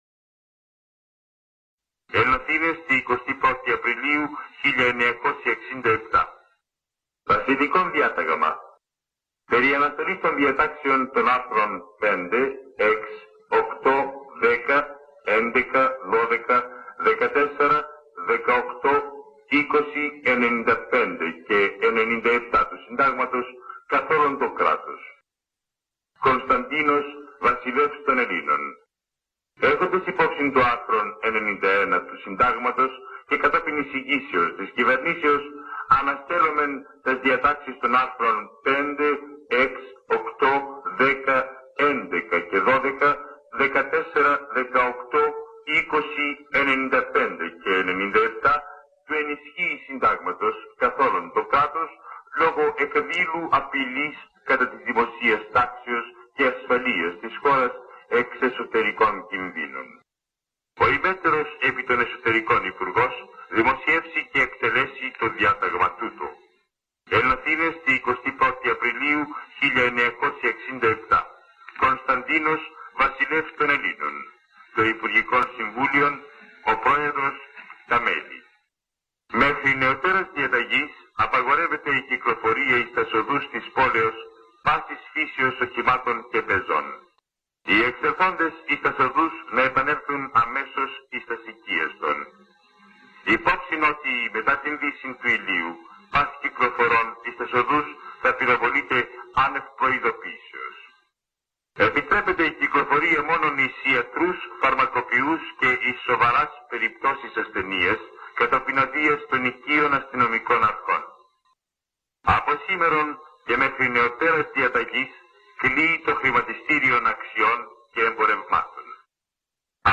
Ηχητικό ντοκουμέντο από διάγγελμα για το πραξικόπημα της 21ης Απριλίου